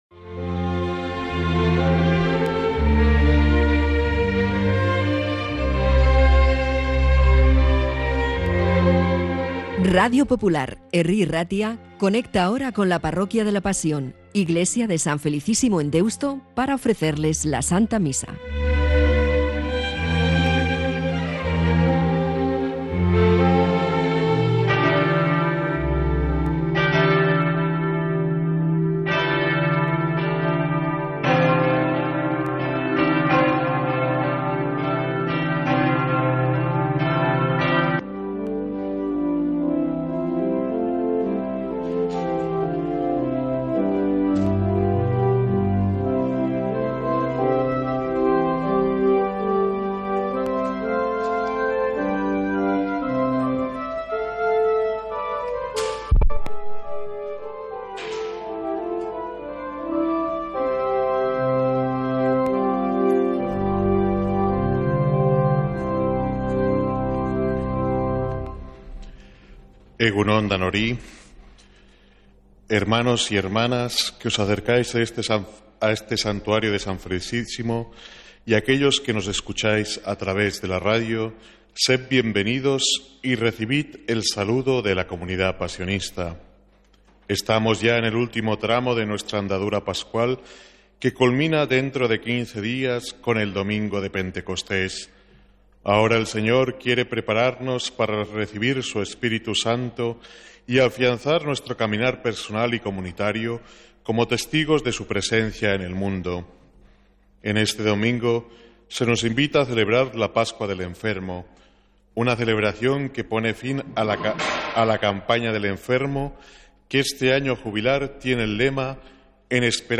Santa Misa desde San Felicísimo en Deusto, domingo 25 de mayo